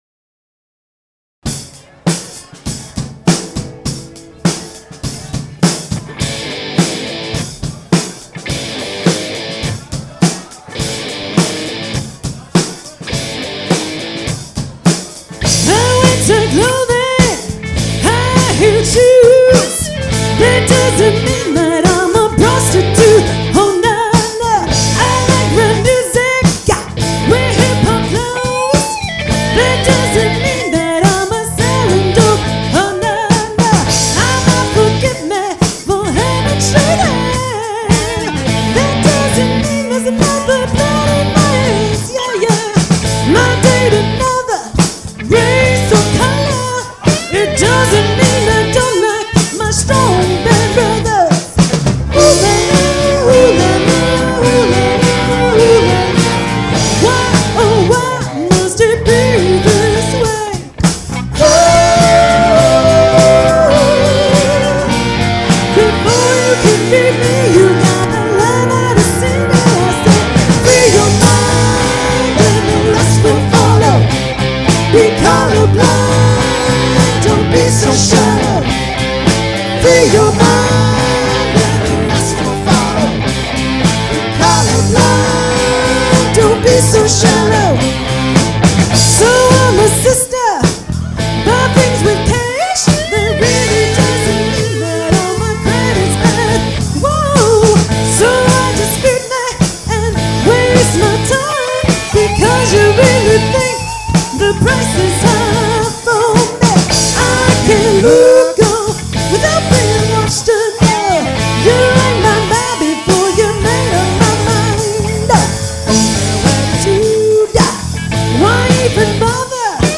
Classic Rock, Modern Hits, and Always A Great Time!
Here's a few live samples of our show.